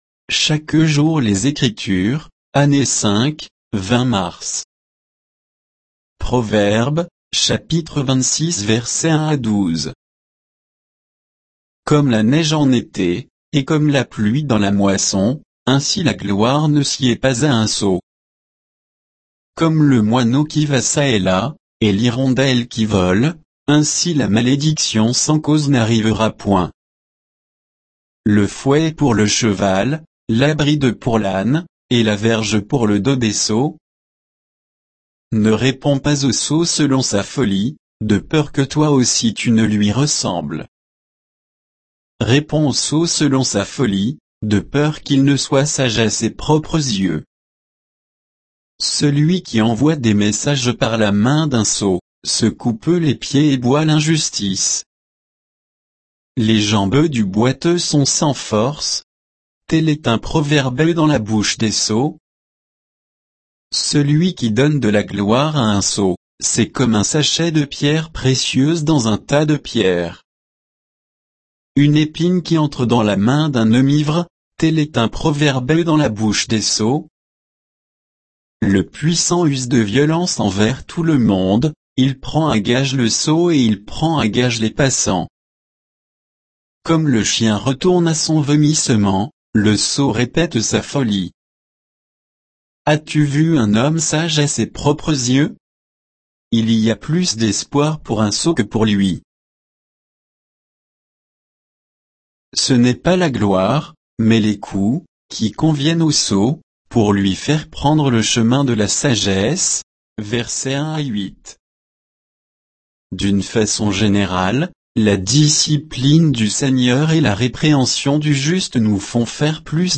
Méditation quoditienne de Chaque jour les Écritures sur Proverbes 26